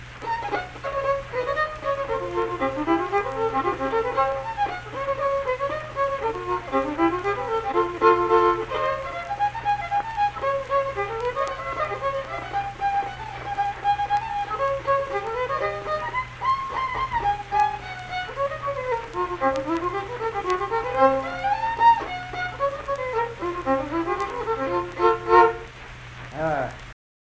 Unaccompanied fiddle music
Verse-refrain 3(2).
Instrumental Music
Fiddle
Saint Marys (W. Va.), Pleasants County (W. Va.)